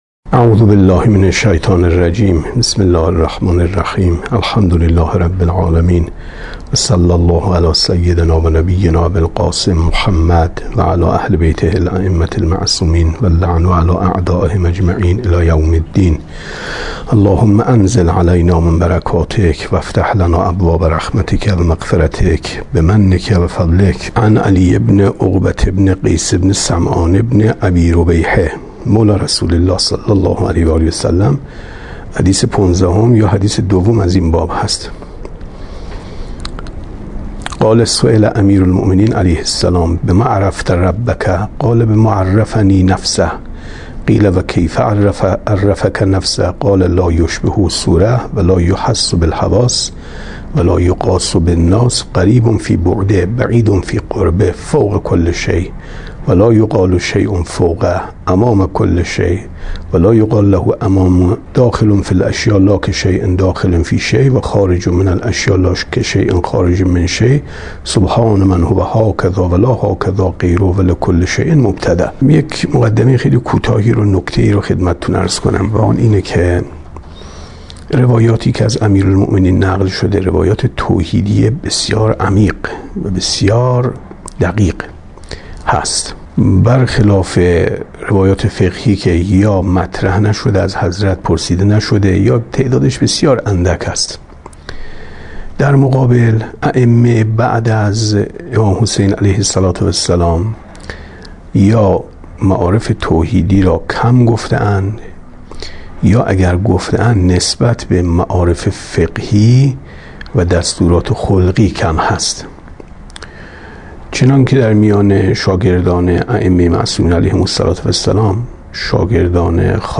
کتاب توحید ـ درس 27 ـ 12/ 8/ 95